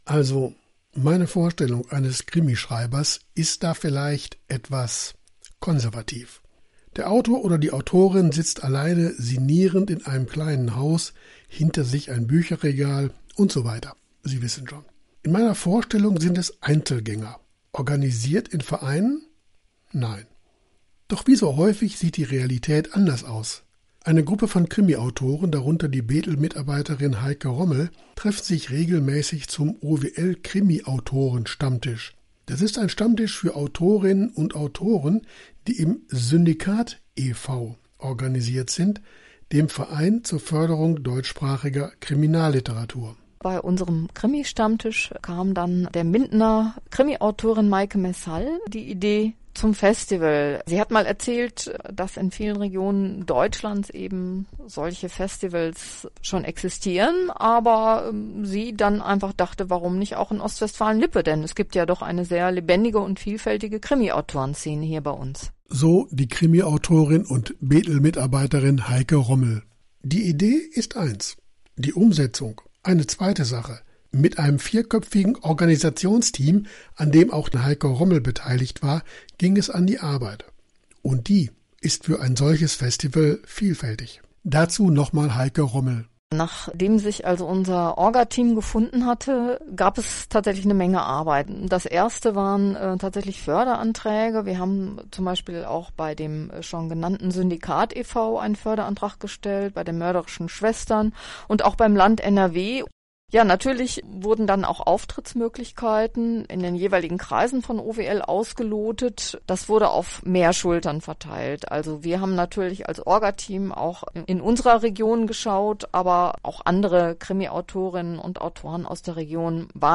Beitrag über das Krimifestival-OWL